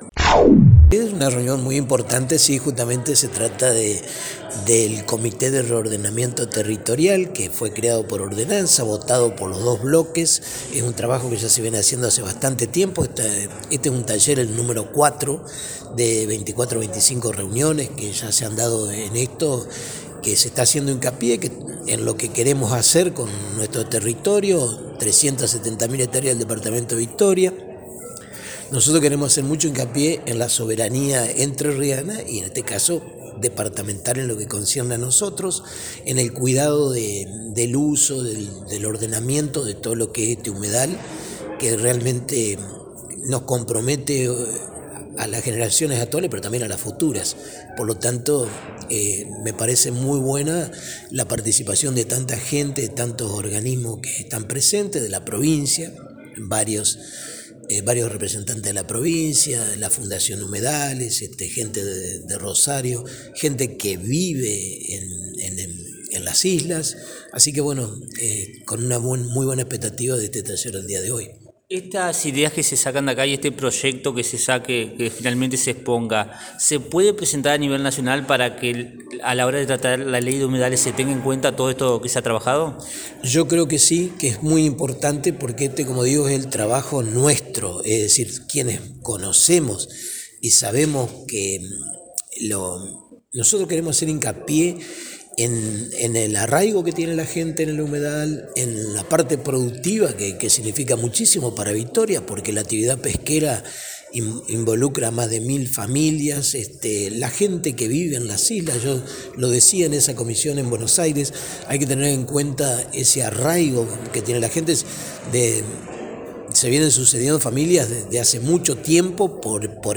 En la mañana de este miércoles, se produjo el cuarto taller en torno al Reordenamiento Territorial de la Reserva de Usos Múltiples de Humedales e Islas. “En este territorio hay vida y se produce, hay que mantenerlo y cuidarlo” dijo el intendente Maiocco en diálogo con este medio.
La reunión se realizó en el Tiro Federal, donde acudieron el Comité de Reordenamiento Territorial, representantes del gobierno local, encabezado por el intendente Domingo Maiocco, representantes provinciales, la Fundación Humedales y habitantes de las islas de Victoria.